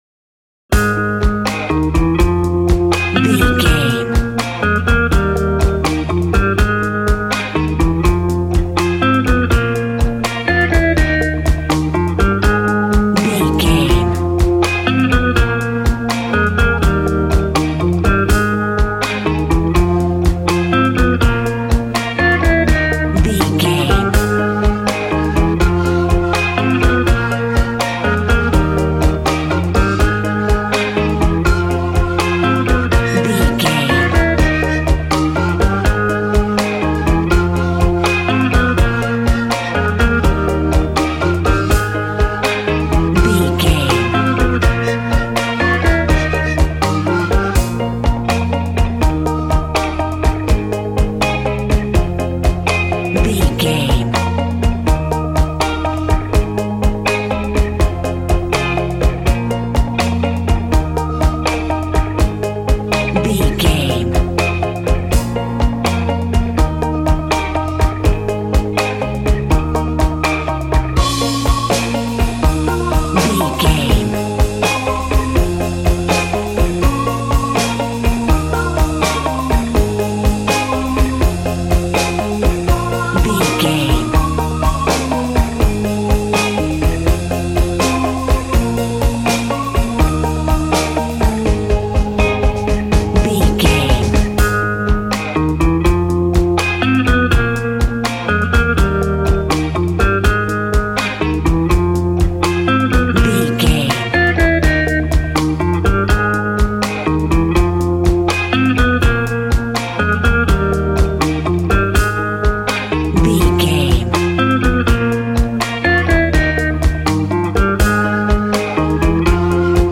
Ionian/Major
A♭
cheerful/happy
double bass
drums
piano